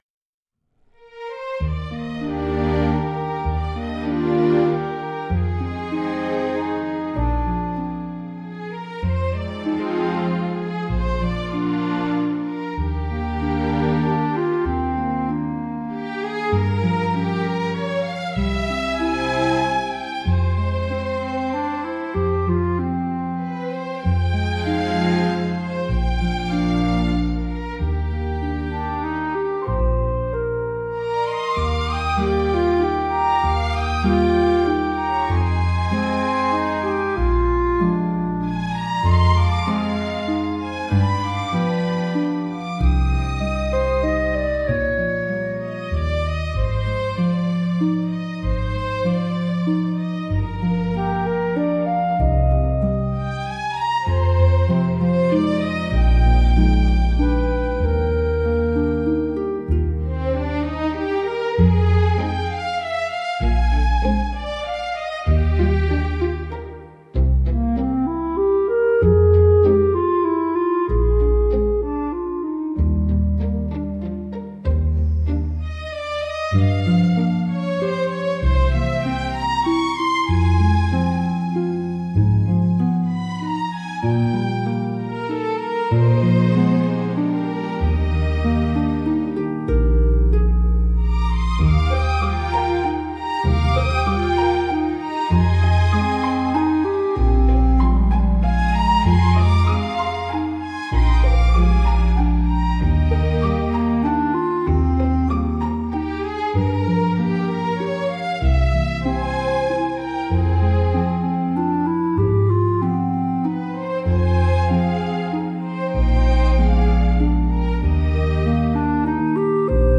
静かで清々しい空気感を演出しつつ、心に明るい希望や期待を芽生えさせる効果があります。